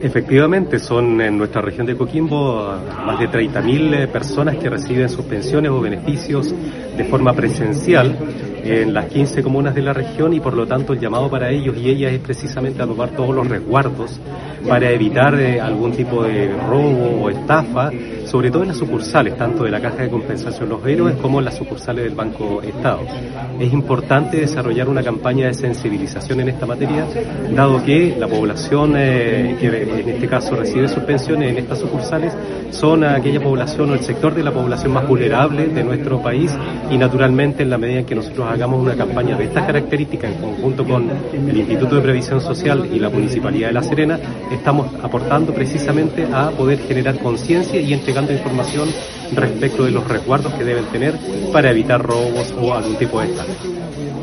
En este mismo sentido el Seremi del Trabajo y Previsión Social, Francisco Brizuela, explicó que